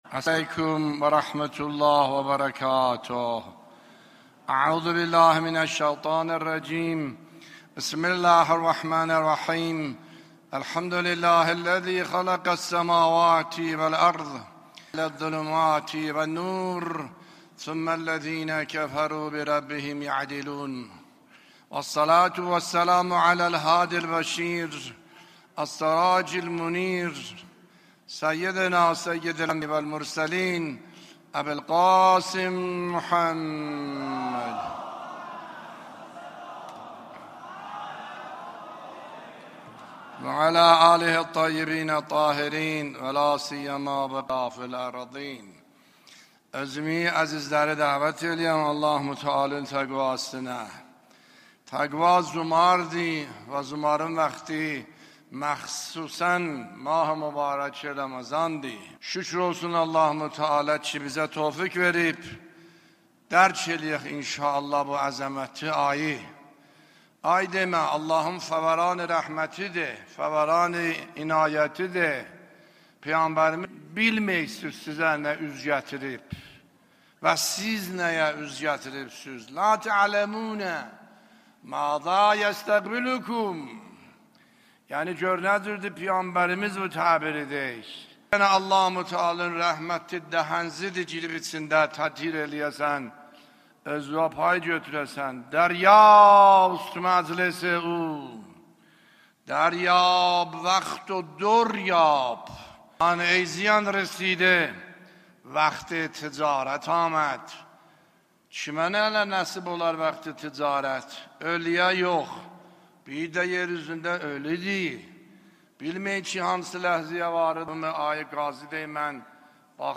بیانات آیت الله سید حسن عاملی نماینده ولی فقیه و امام جمعه اردبیل در خطبه های نماز جمعه در 26 اسفند 1401